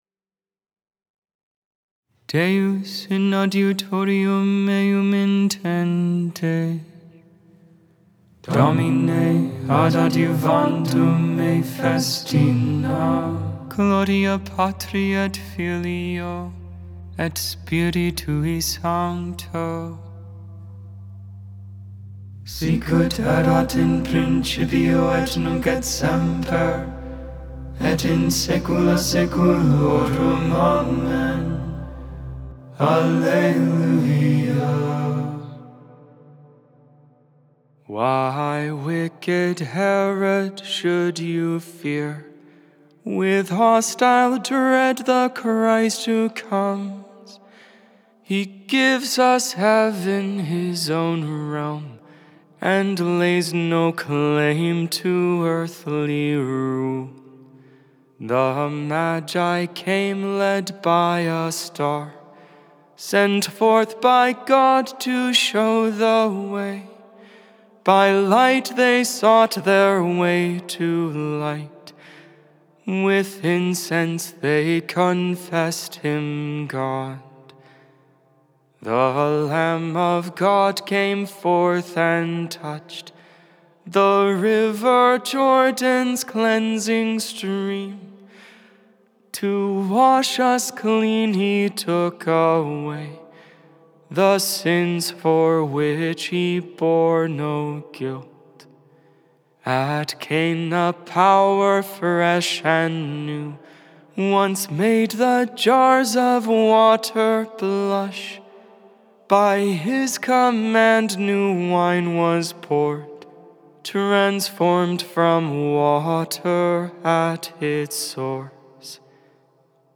1.9.25 Vespers, Thursday Evening Prayer of the Liturgy of the Hours